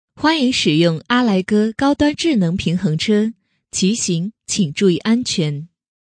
【女57号彩铃】智能播报
【女57号彩铃】智能播报.mp3